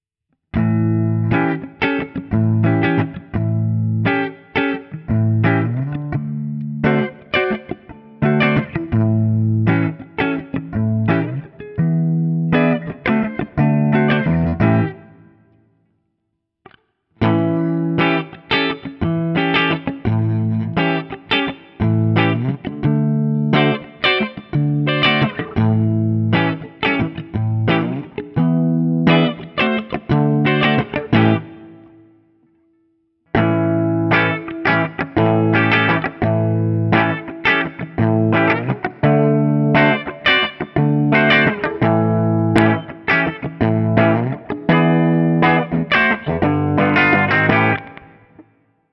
This is an aggressive sounding guitar that’s a bit dark for my tastes, a fact that I attribute to the Guild XR7 pickups and the mahogany body.
ODS100 Clean
7th Chords
As usual, for these recordings I used my normal Axe-FX II XL+ setup through the QSC K12 speaker recorded direct into my Macbook Pro using Audacity.
For each recording I cycle through the neck pickup, both pickups, and finally the bridge pickup.